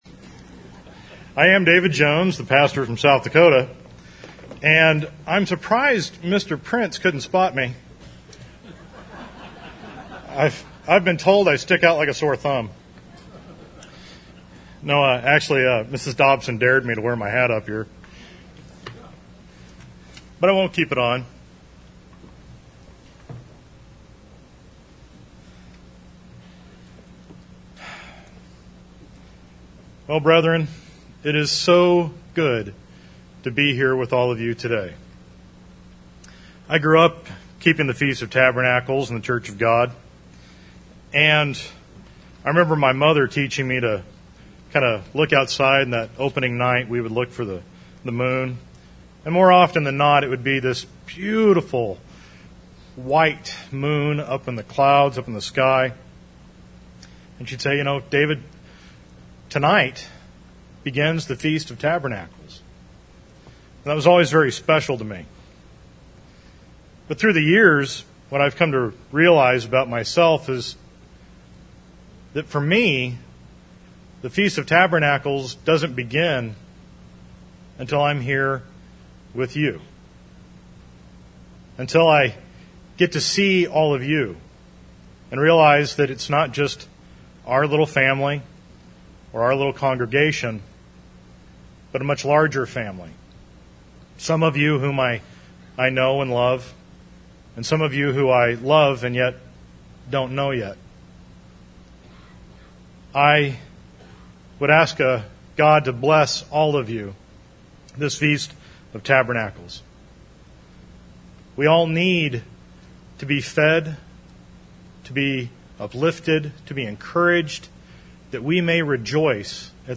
This sermon was given at the Branson, Missouri 2016 Feast site.